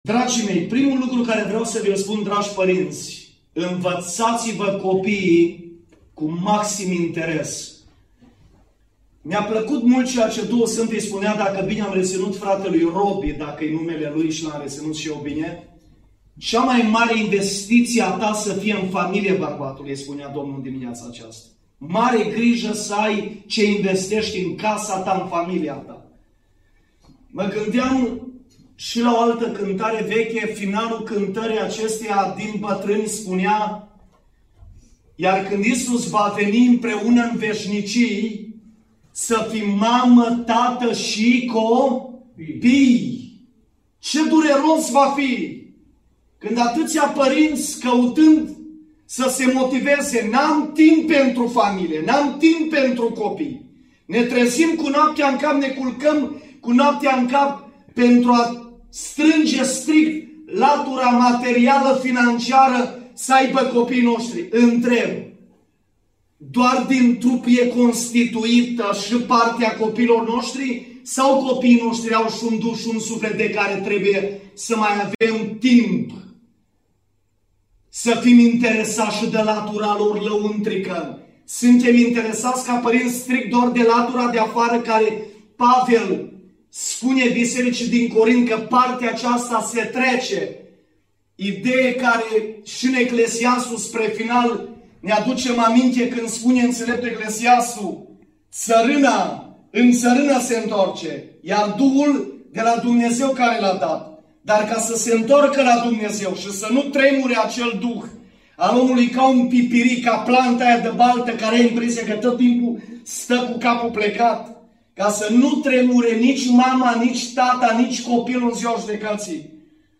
Acest mesaj spiritual a fost structurat in 4 parti: 1, 2, 3 si partea a 4-a.